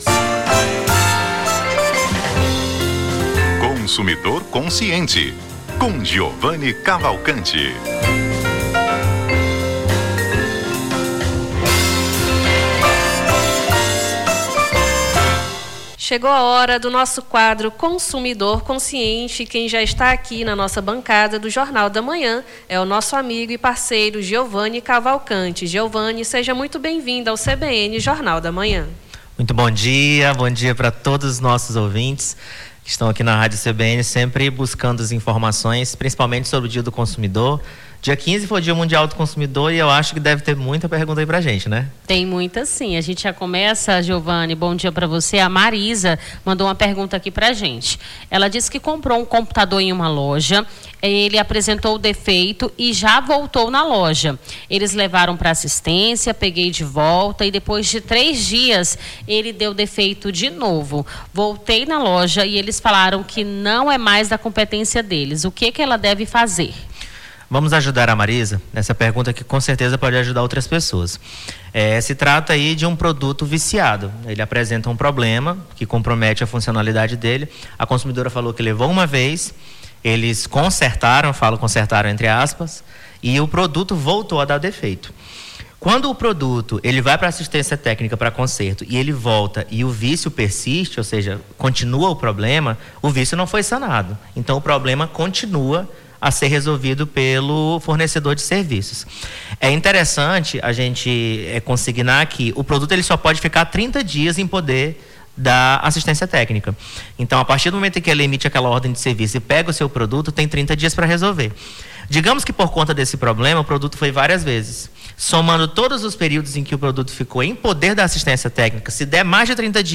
Consumidor Consciente: advogado esclarece dúvidas sobre direito do consumidor